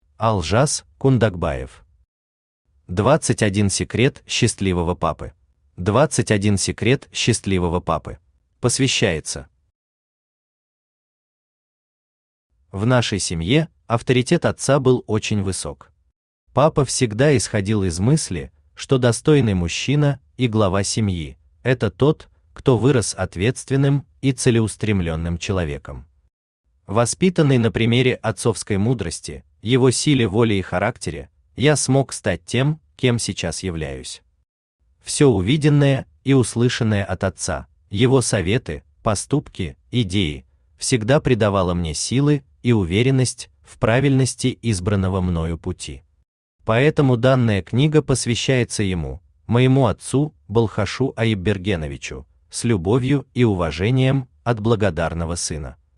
Аудиокнига 21 секрет счастливого папы | Библиотека аудиокниг
Читает аудиокнигу Авточтец ЛитРес.